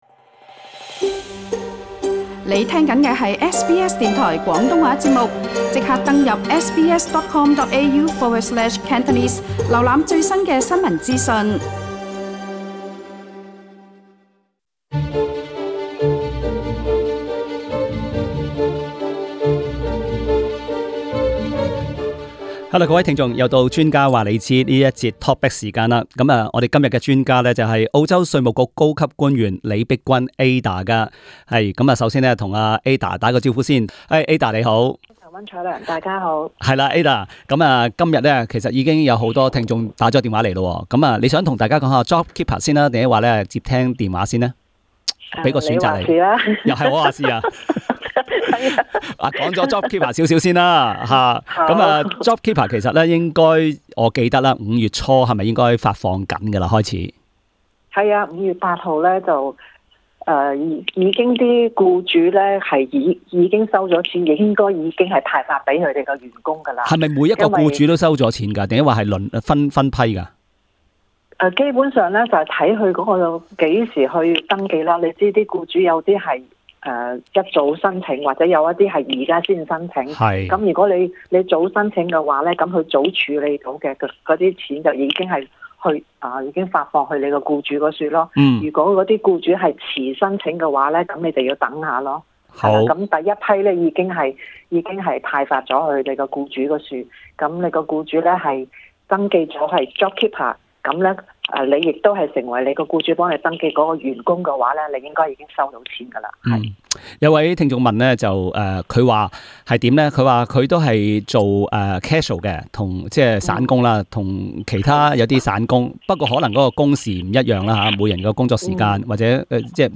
稅務局官員解答常見 Jobkeeper 補貼「疑問」